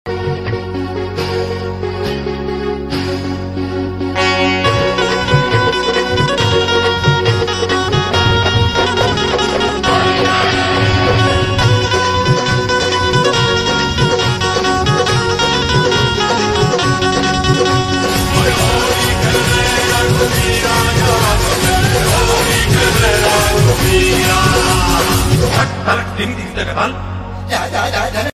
Experience the energetic rhythm of Holi